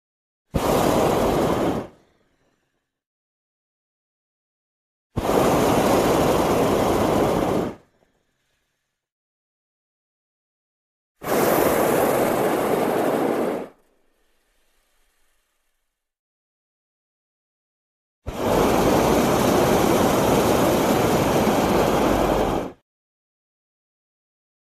Шум полета на воздушном шаре в небесной выси аэростата